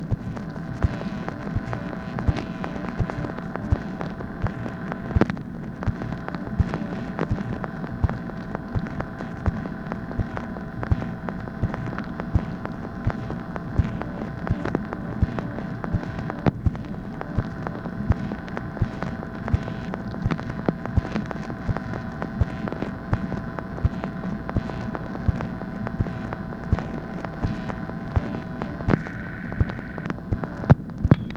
MACHINE NOISE, November 6, 1968
Secret White House Tapes | Lyndon B. Johnson Presidency